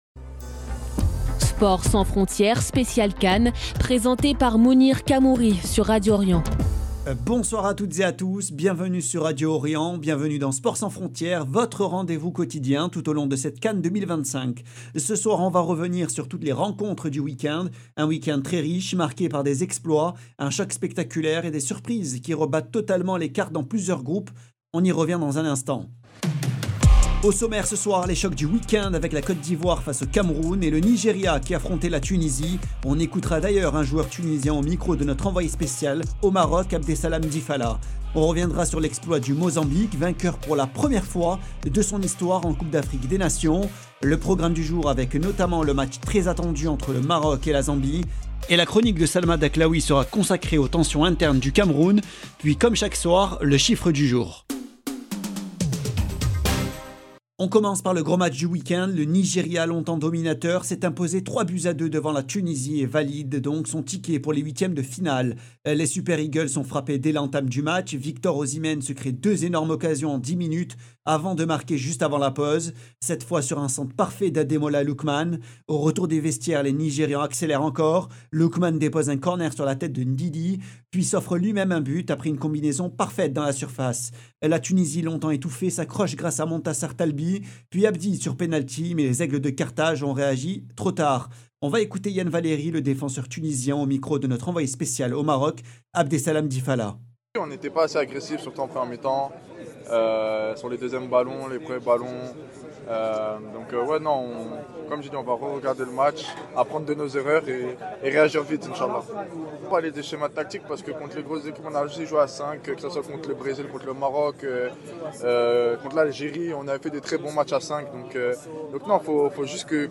Sport sans frontières - émission spéciale pour la CAN